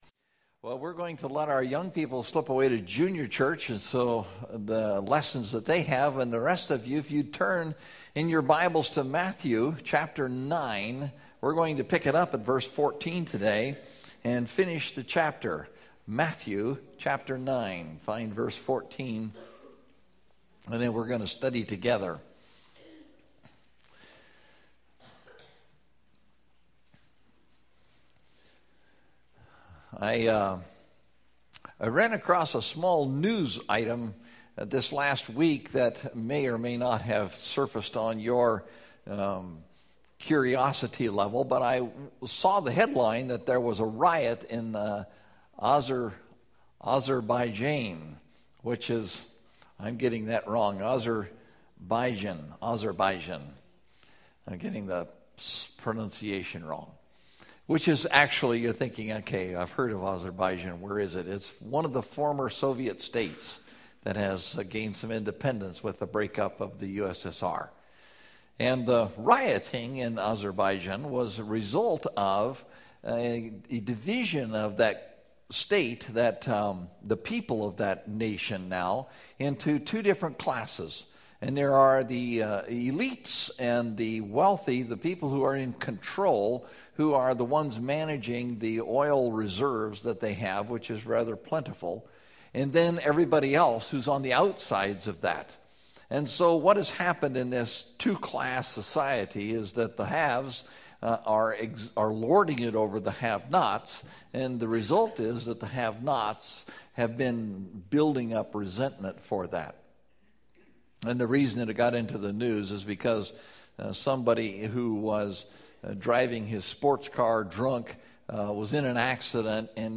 Stretchy Wineskins (Matthew 9:14-38) – Mountain View Baptist Church